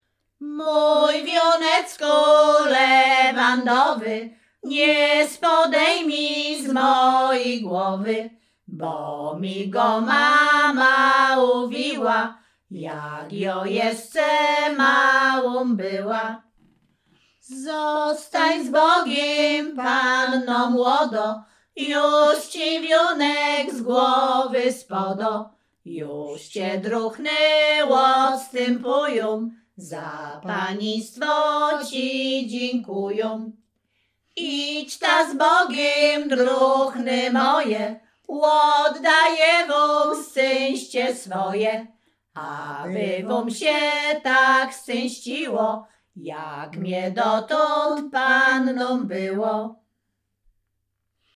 Śpiewaczki z Chojnego
województwo łódzkie, powiat sieradzki, gmina Sieradz, wieś Chojne
wesele weselne oczepinowe panieński wieczór